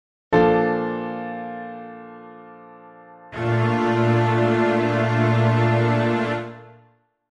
B♭maj7
Click to hear a B♭maj7 Chord.
major7_chord.mp3